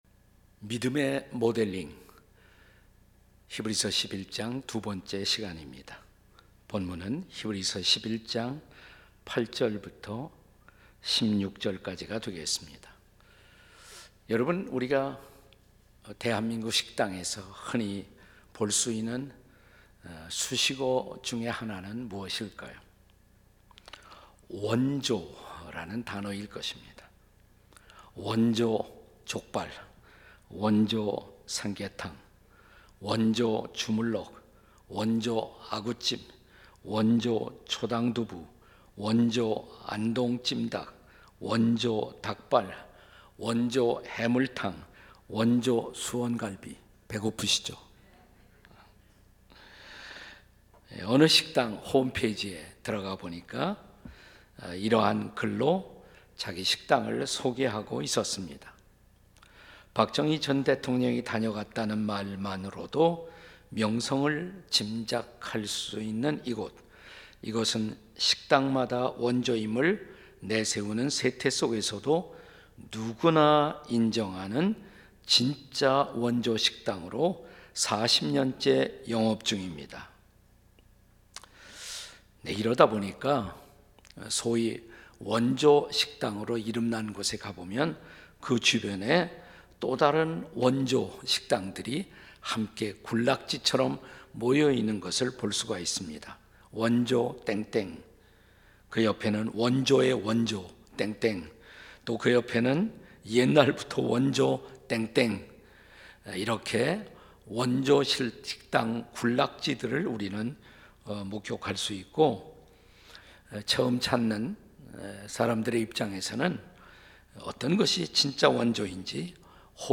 설교 : 주일예배 히브리서 - (15) 믿음의 모델링에 도전하라2.